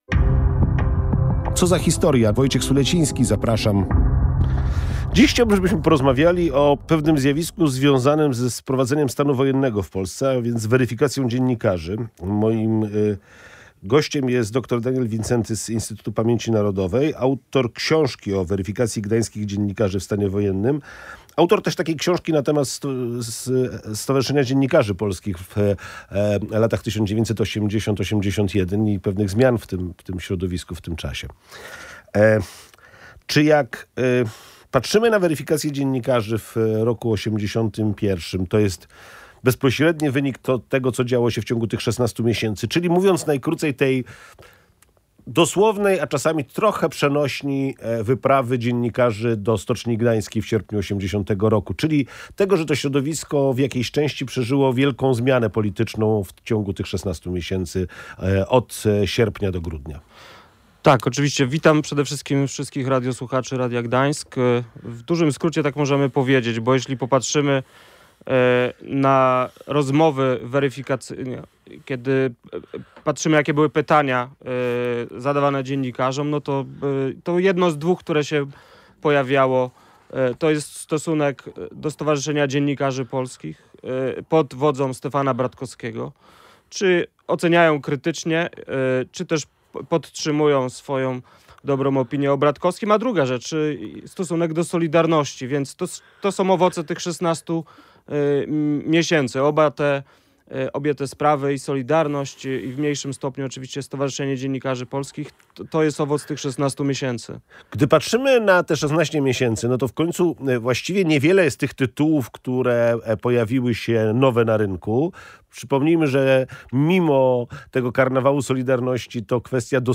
Audycja Co za Historia była poświęcona weryfikacji dziennikarzy w stanie wojennym.